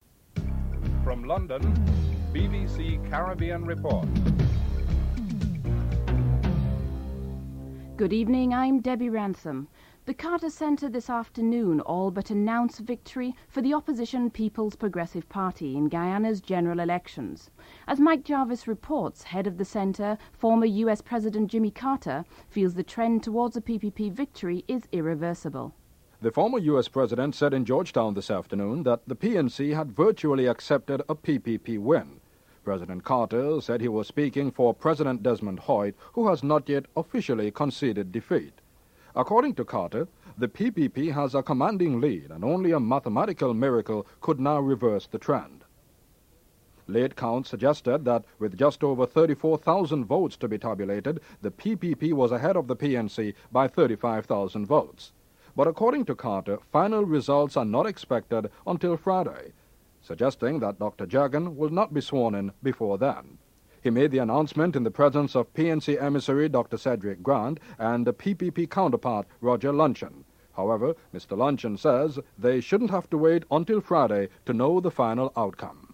2. Cheddi Jagan, Leader of the People's Progressive Party talks about why the party does not want to wait till Friday for him to be sworn in as president. (01:52-06:12)